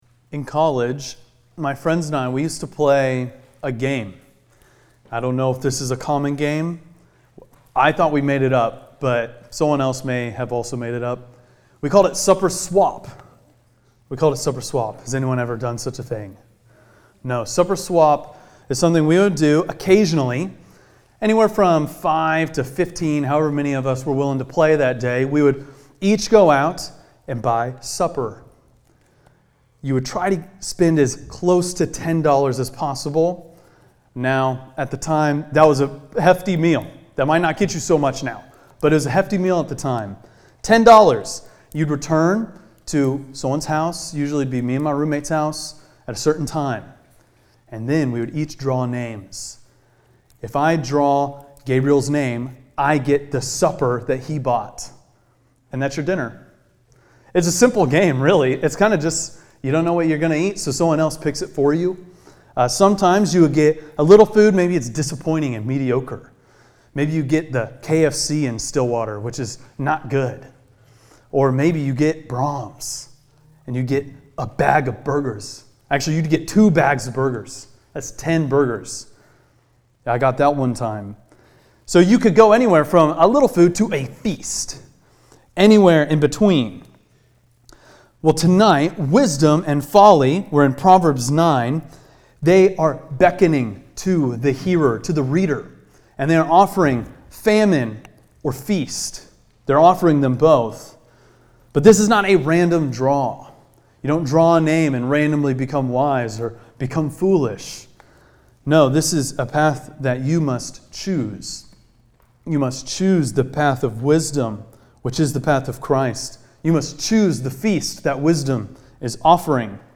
preaches through Proverbs 9.